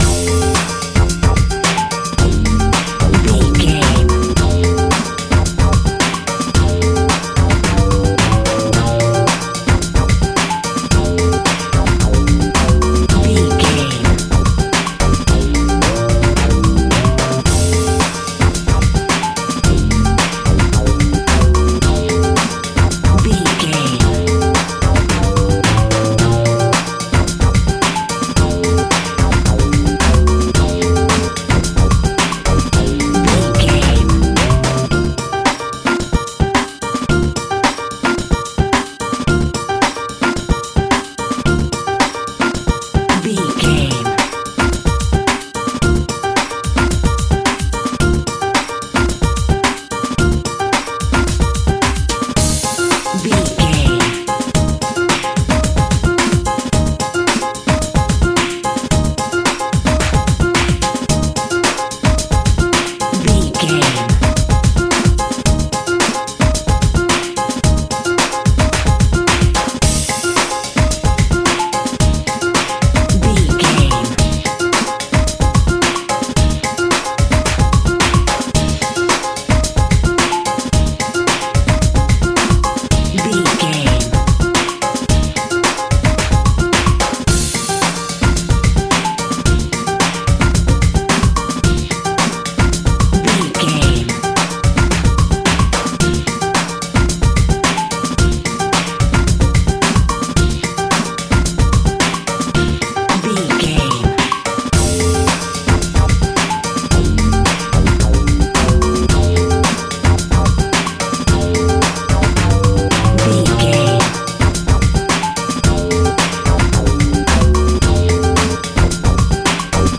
Uplifting
Ionian/Major
B♭
drums
piano
bass
guitar
brass
sax
trumpet
trombone